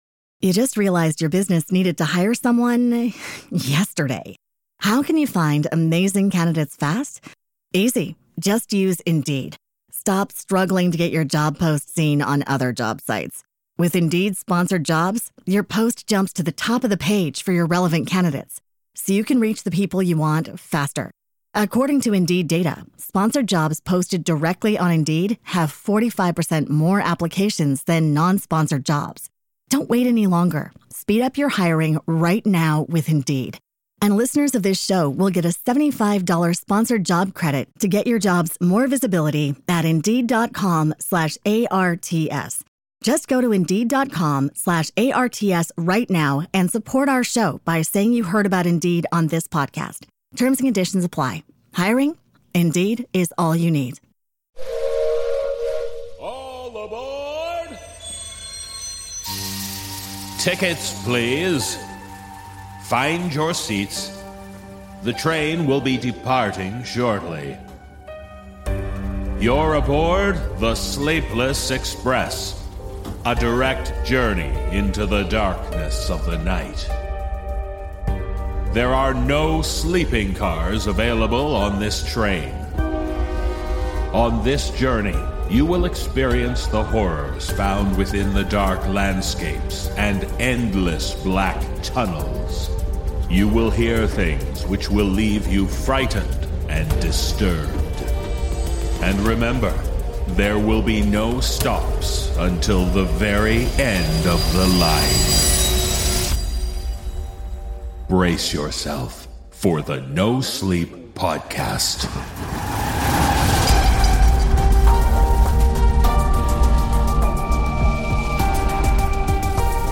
Musical score